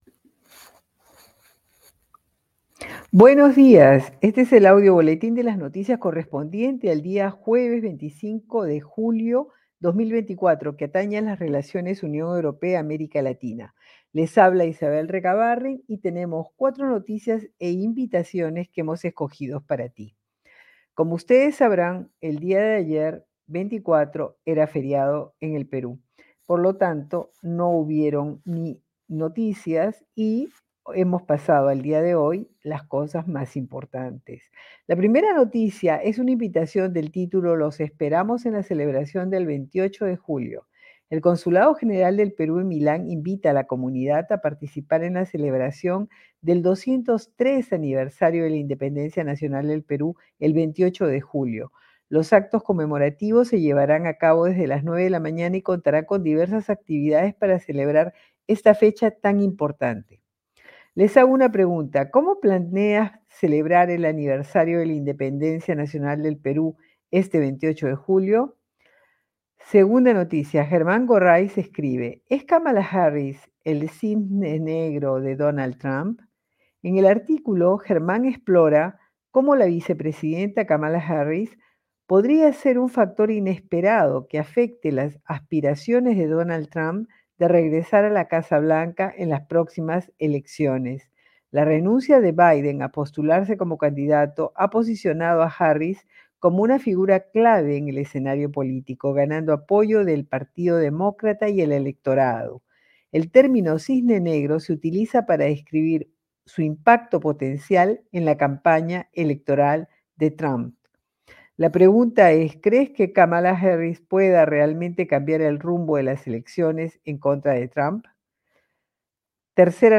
Audio Boletín de Noticias del día Jueves 25 de Julio 2024